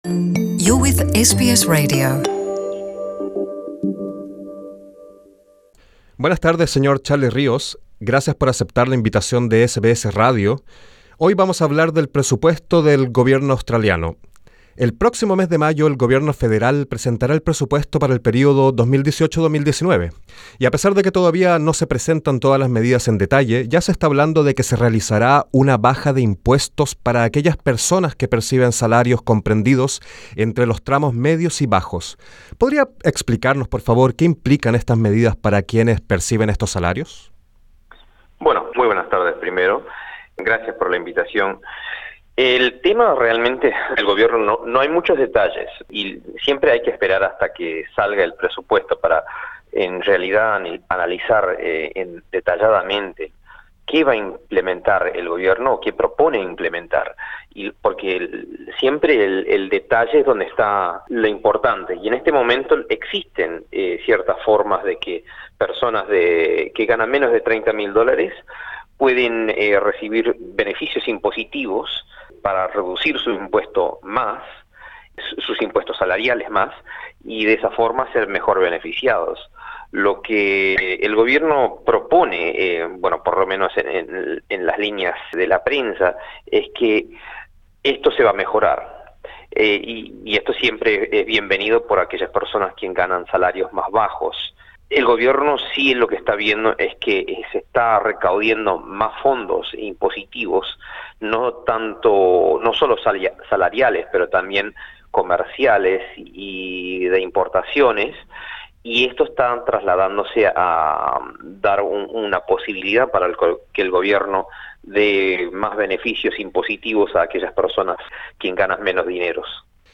En esta entrevista conversamos con el experto en economía